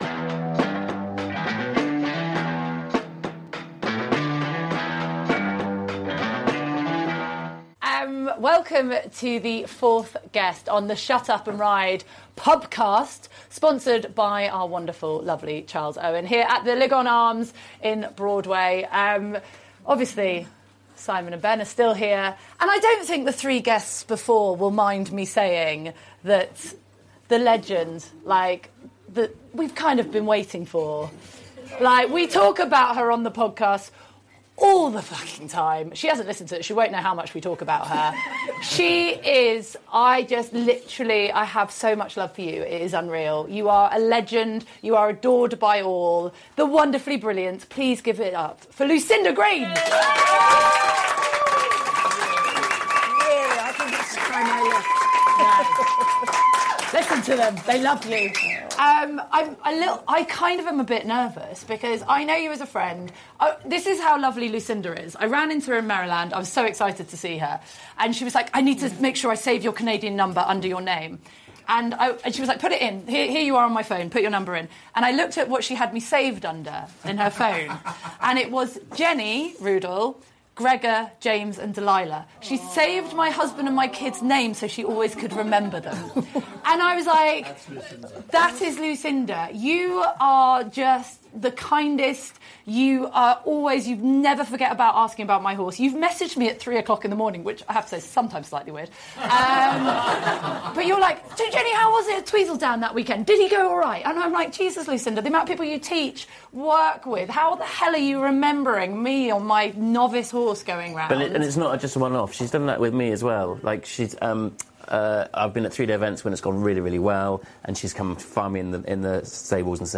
Recorded live at the legendary Lygon Arms, this episode is everything we dreamed a Pub Pod could be: honest, hilarious, emotional, and totally unforgettable. Lucinda had the room roaring with laughter one minute and wiping away tears the next, as she shared stories that span decades of riding, falling, winning, and loving horses with her whole heart.